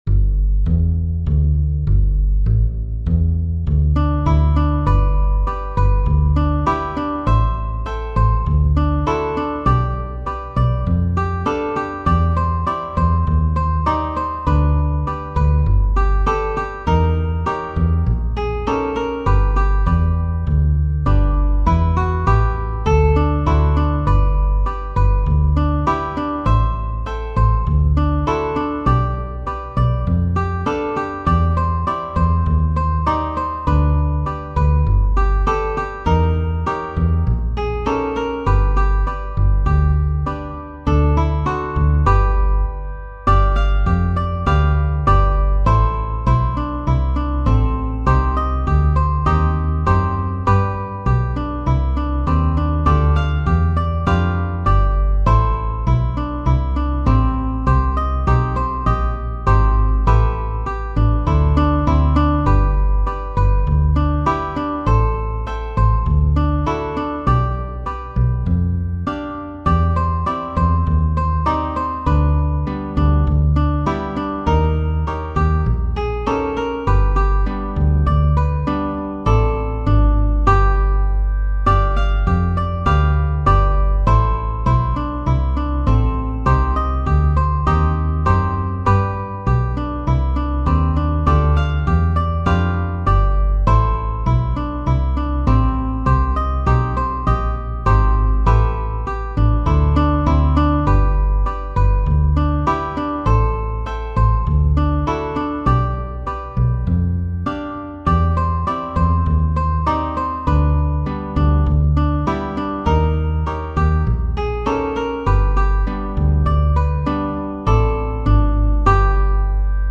Makin' Whoopee - Eynsham Ukulele Quartet
Rhythm Uke
Bass